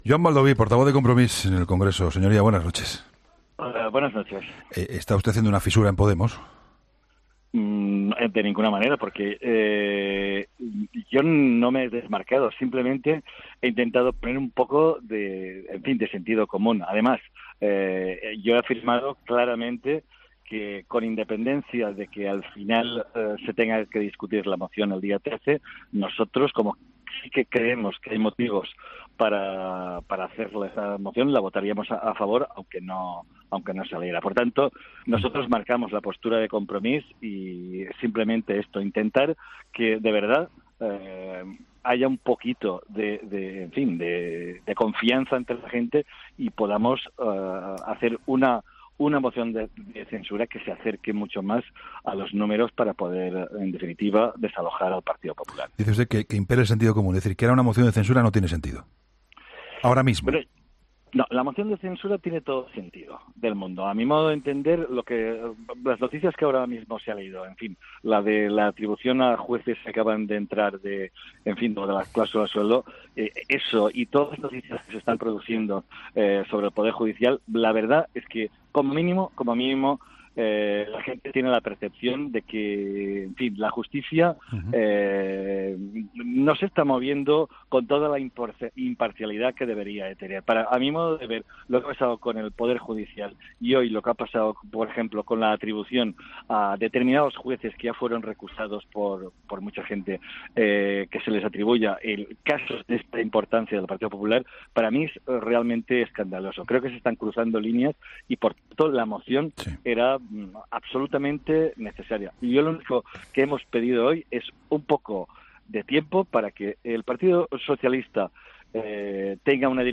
Entrevista a Joan Baldoví